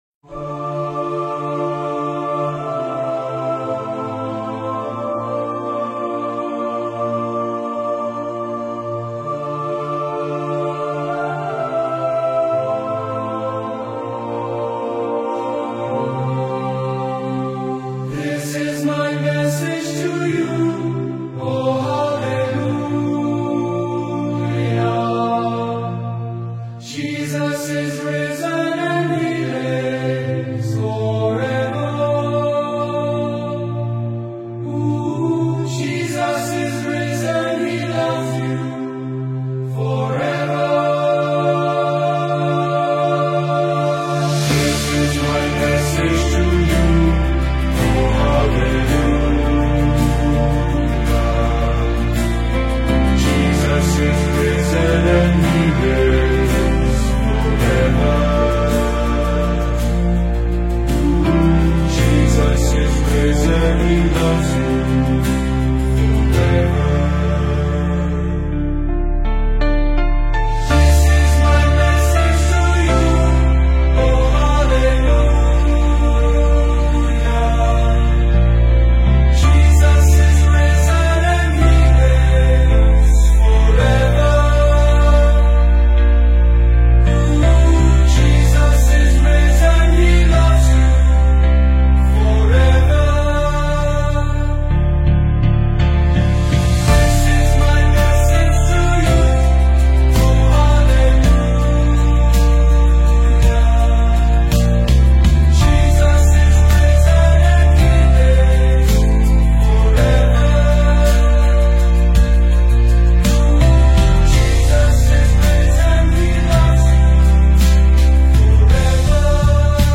The Risen King - Catholic Chior